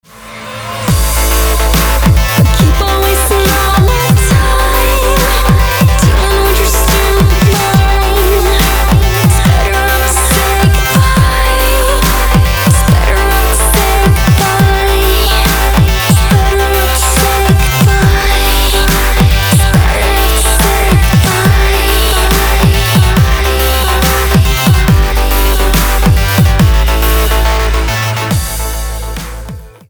club музыка